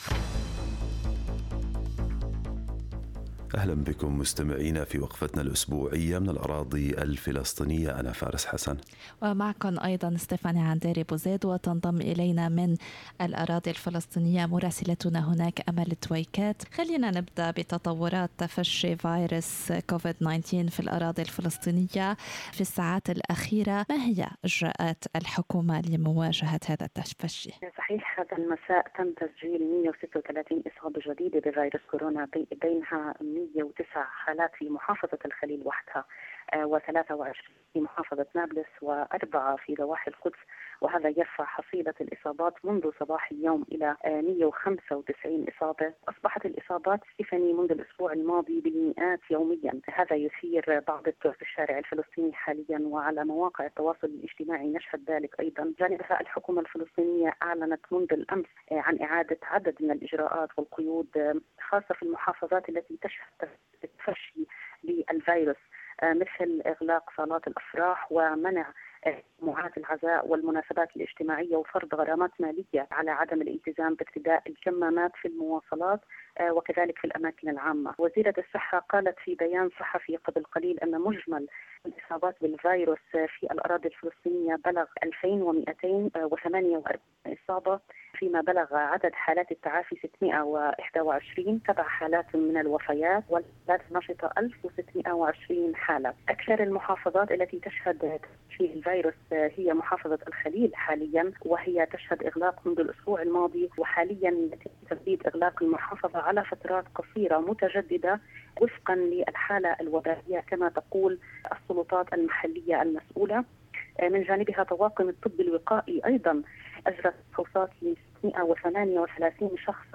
من مراسلينا: أخبار الأراضي الفلسطينية في أسبوع 29/06/2020